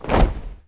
AFROFEET 2-R.wav